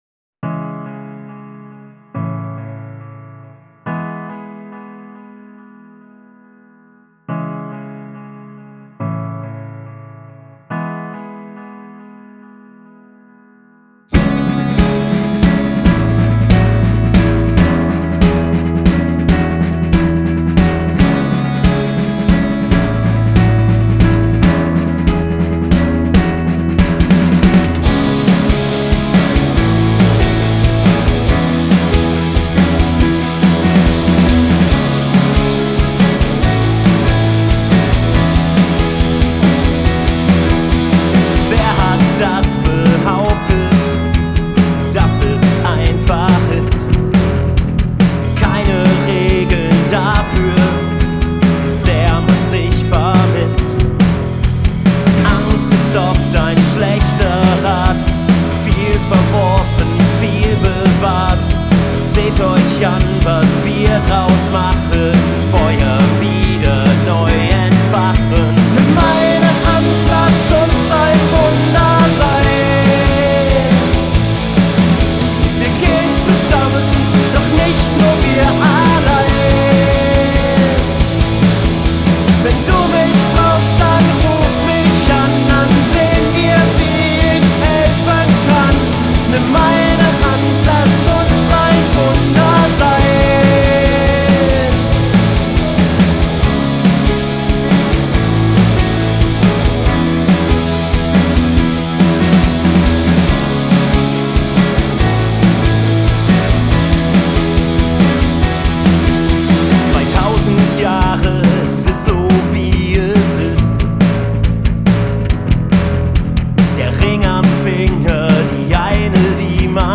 Indie-Rock / Wave - Ein Wunder
Selbst der Gesang ist jetzt gestimmt... Eigentlich könnte der Mix noch etwas mehr Druck in den Bässen, wie auch noch eine Öffnung im oberen Mittenbereich vertragen.
Jetzt ist es trockener. Backings sind eigentlich schon drin im Refrain, ich habe sie nochmal etwas präsenter gemacht.